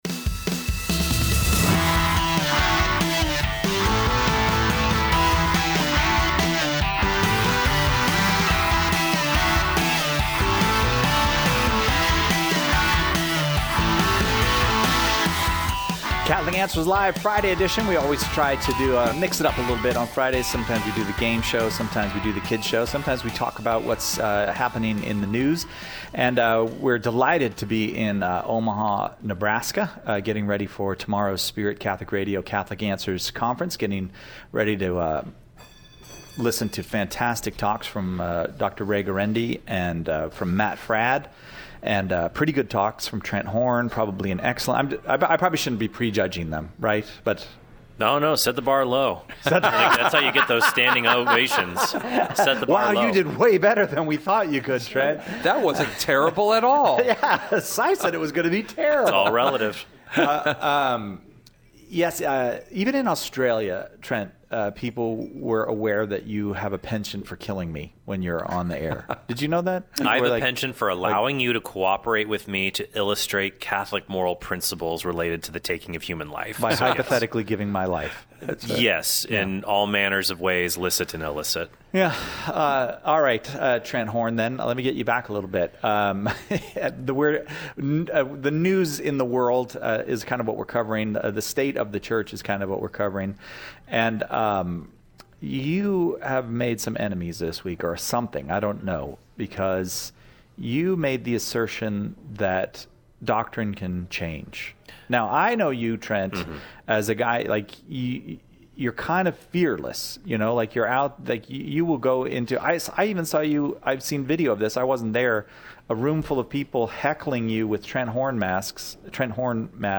Panel Show